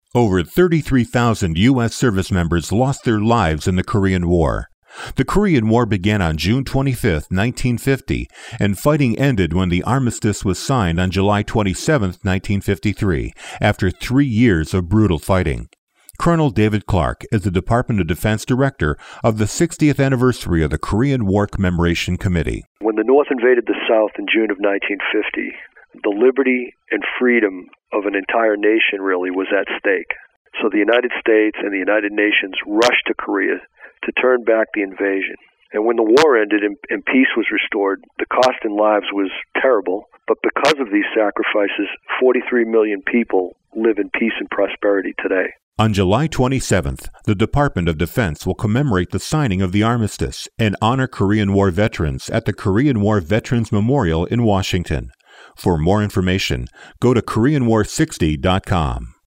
June 25, 2013Posted in: Audio News Release